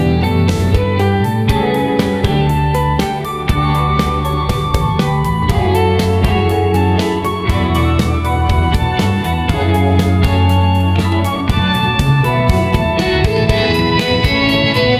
soft_rock_serenade.wav